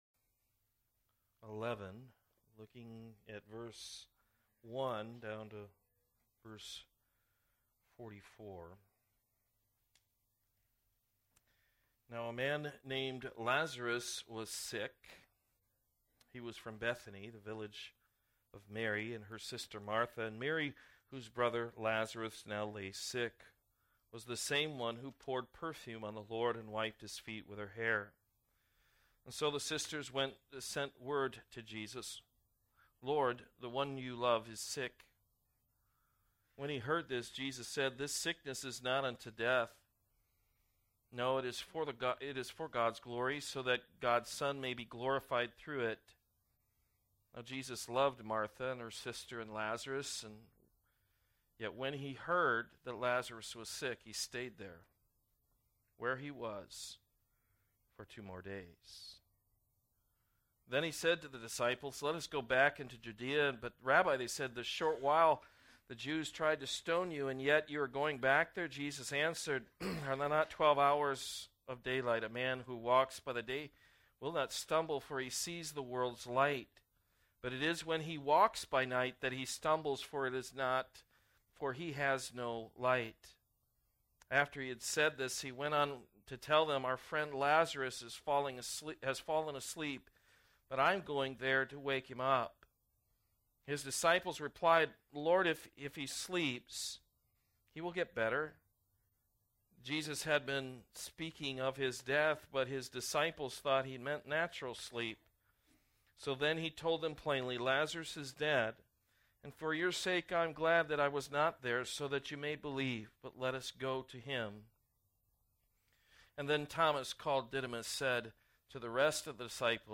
John 11:1-44 Service Type: Morning Service Within the reality of Divine Providence